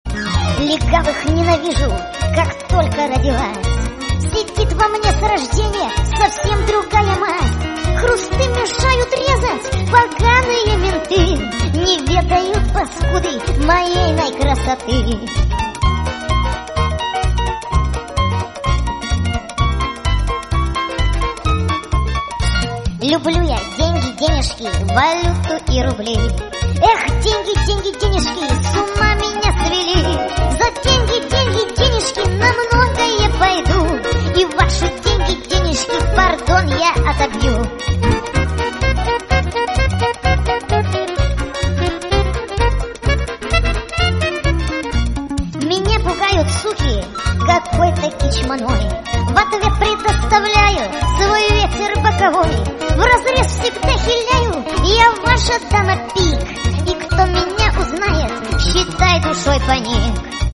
веселые
смешной голос